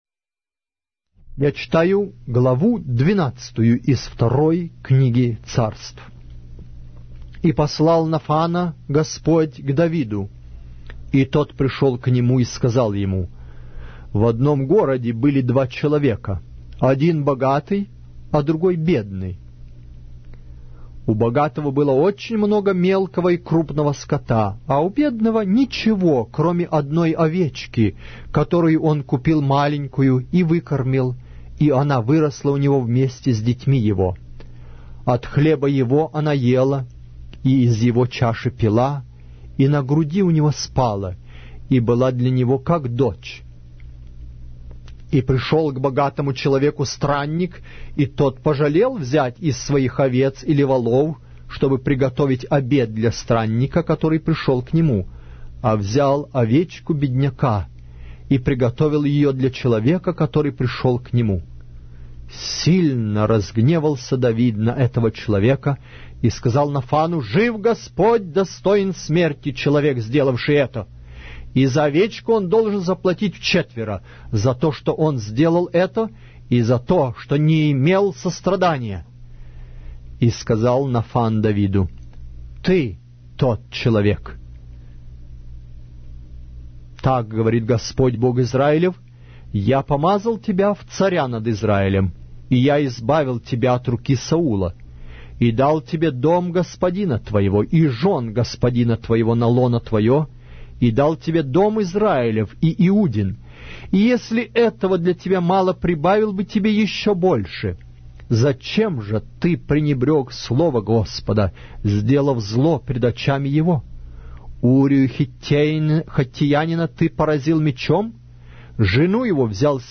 Аудиокнига: 2-я Книга Царств